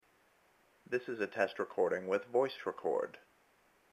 So to get decent volume I had to run the 1/8 inch cable from my iPhone through my M-Audio MobilePre USB audio interface.
In each case, I pointed the microphone (on the bottom of the iPhone) toward me and held it about six inches away. I didn't run any compression, signal gain or otherwise mess with the recordings other than to crop them.
voicerecord-sample.mp3